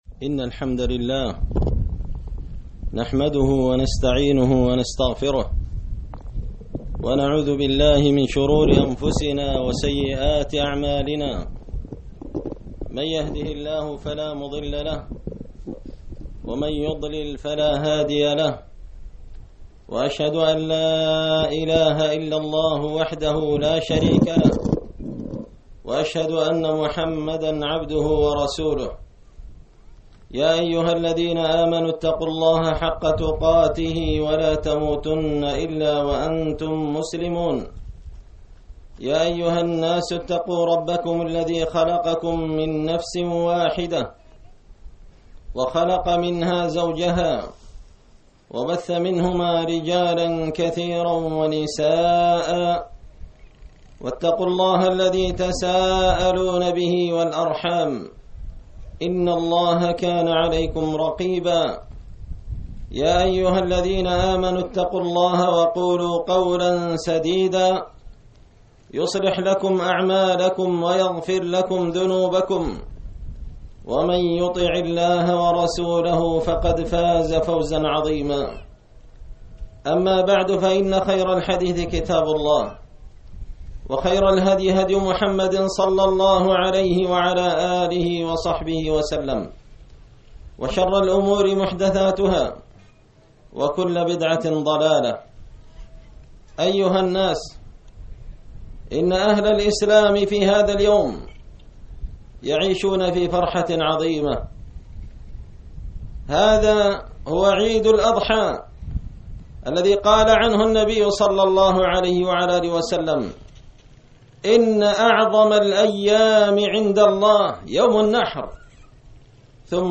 خطبة عيد الأضحى
دار الحديث بمسجد الفرقان ـ قشن ـ المهرة ـ اليمن
خطبة-عيد-الأضحى1443.mp3